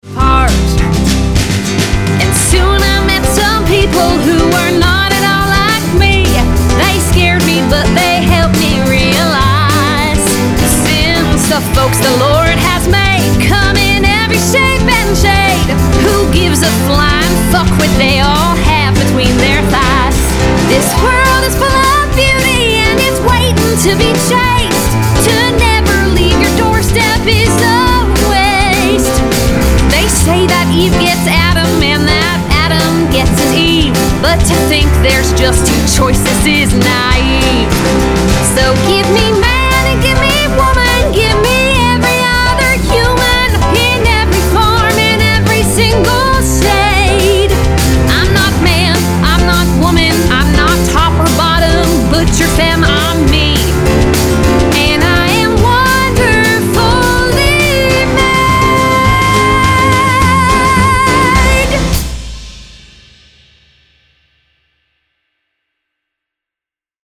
Concept Cast Recording